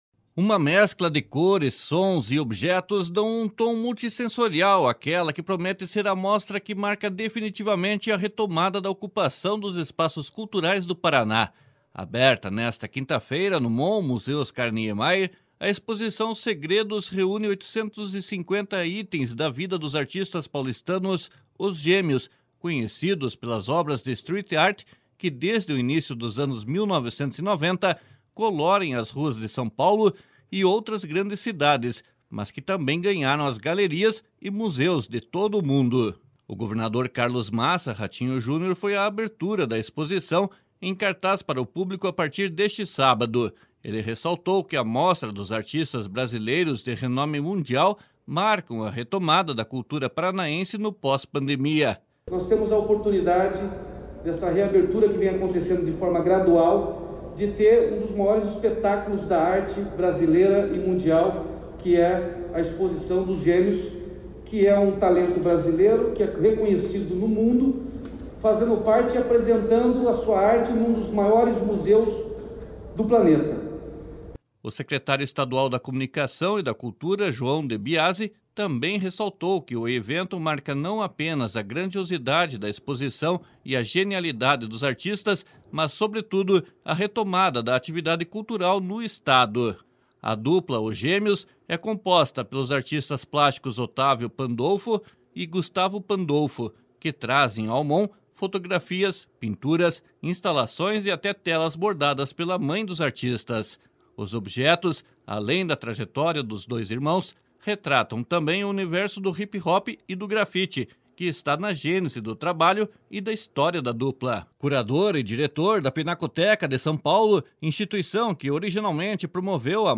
Ele ressaltou que a mostra dos artistas brasileiros de renome mundial marcam a retomada da cultura paranaense no pós-pandemia. //SONORA RATINHO JUNIOR//